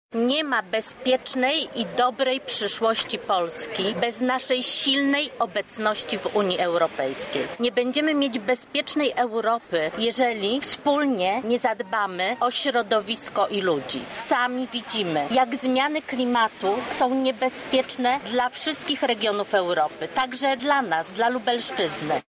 Więcej na ten temat mówi Bożena Lisowska, radna sejmiku województwa lubelskiego kandydująca do parlamentu europejskiego: